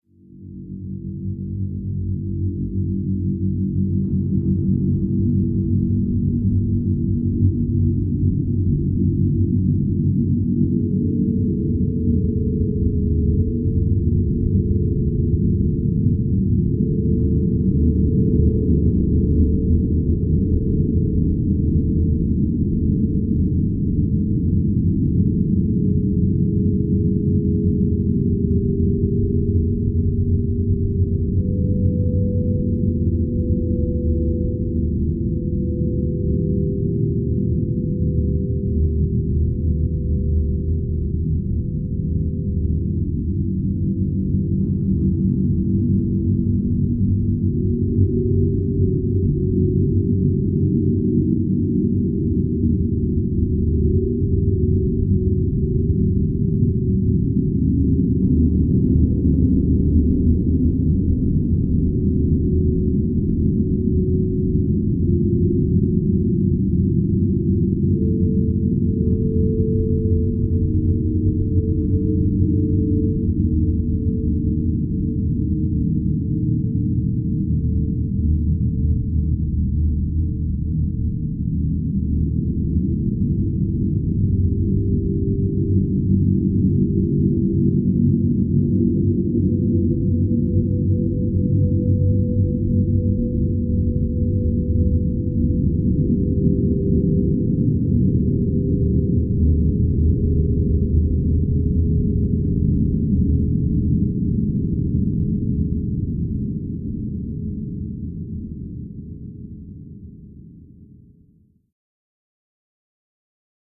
Huge Space Ship Interior Ambience Space Ship, Sci-fi, Interior